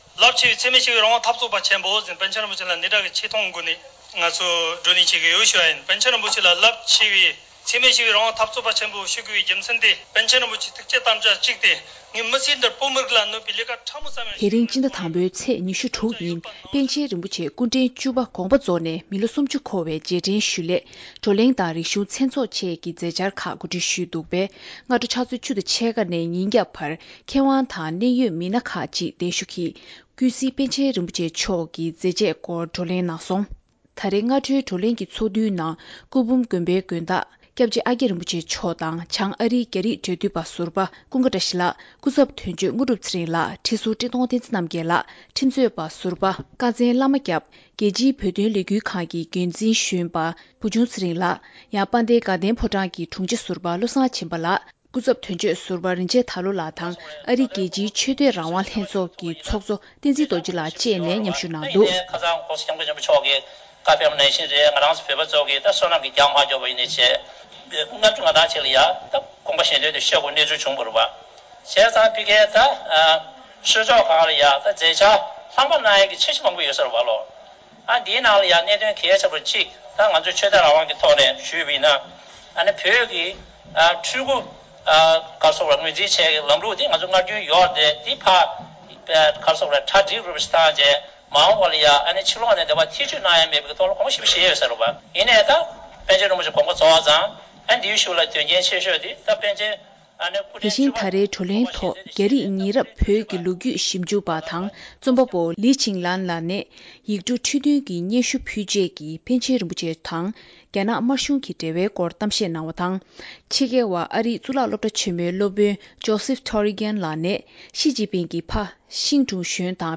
ནིའུ་ཡོག་ཏུ་༧པཎ་ཆེན་རིན་པོ་ཆེ་མཆོག་སྐུ་གཤེགས་ནས་ལོ་ངོ་༣༠འཁོར་བའི་རྗེས་དྲན་སྲུང་བརྩི་ཞུས་པ། བགྲོ་གླེང་གི་ལས་རིམ།